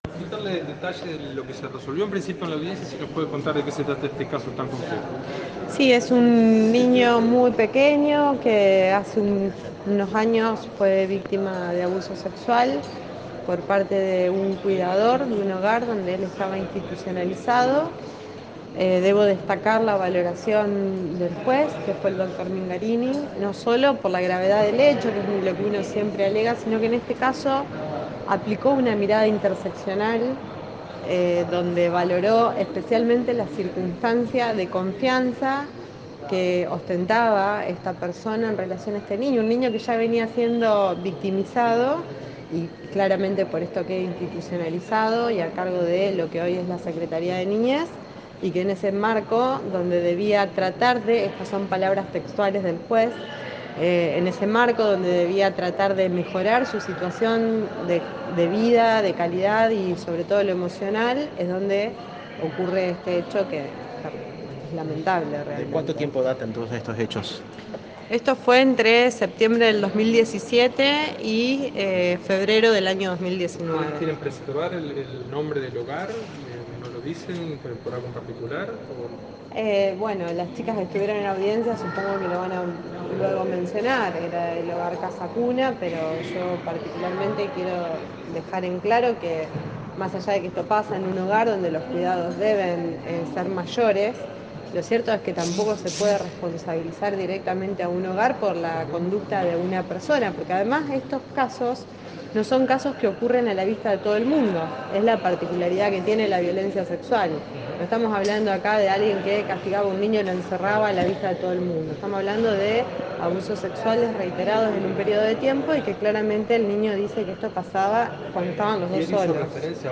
La palabra de la fiscal Alejandra Del Río Ayala: